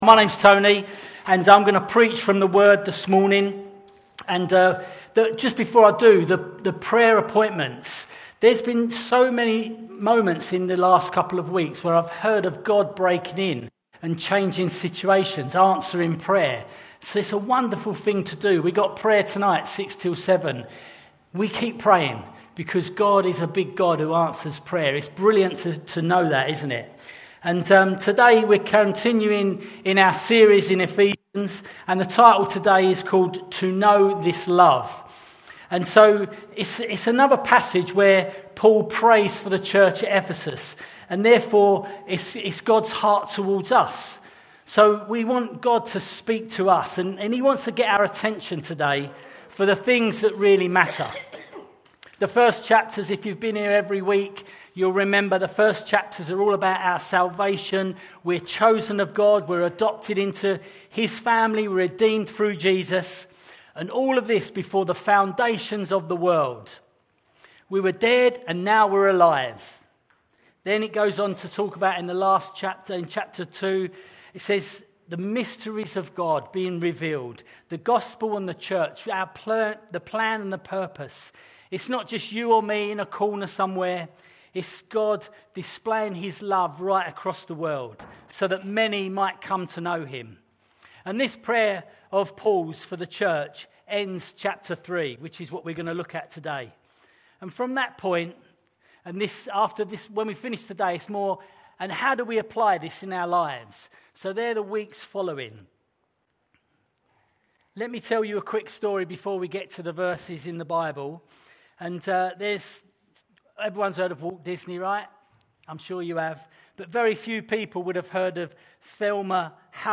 Grace Church Chichester and Havant
Grace Church Sunday Teaching